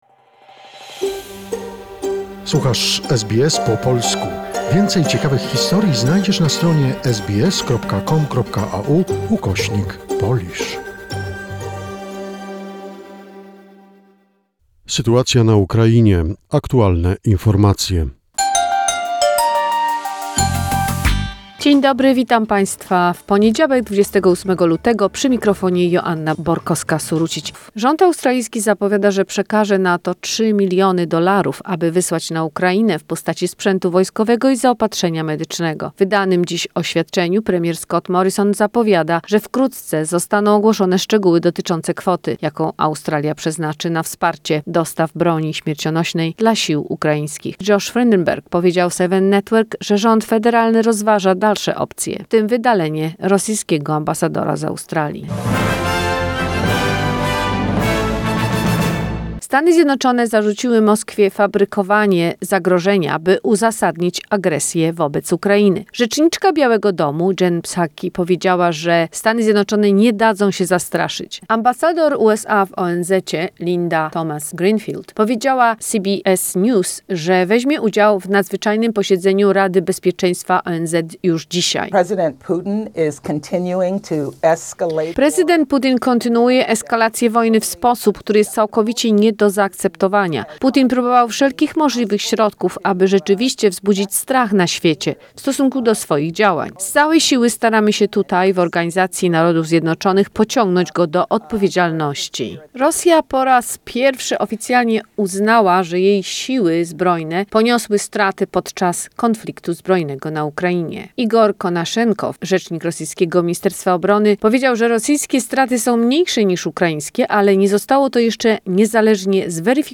The most recent information and events about the situation in Ukraine, a short report prepared by SBS Polish.